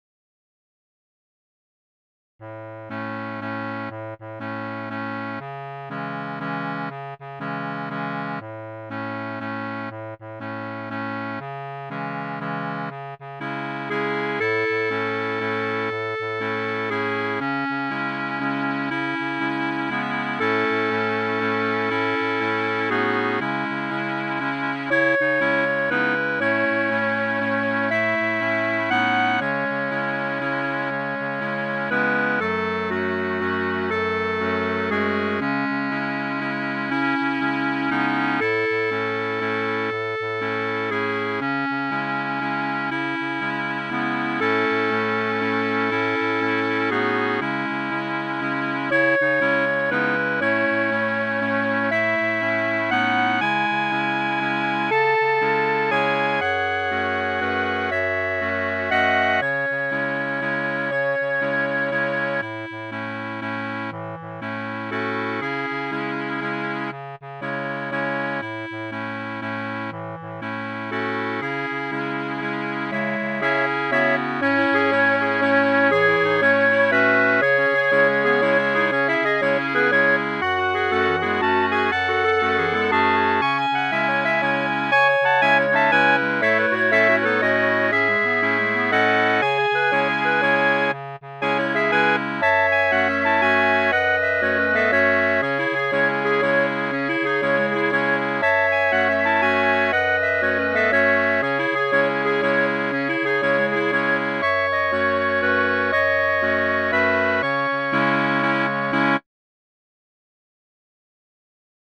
Title Requiem For A Clown Opus # 59 Year 0000 Duration 00:01:52 Self-Rating 4 Description Sadly, an eightsome of clarinet-playing clowns has lost one of its members. However, the show must go on. mp3 download wav download Files: wav mp3 Tags: Clarinet Plays: 2422 Likes: 0